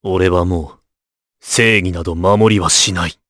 Clause_ice-vox-select_jp.wav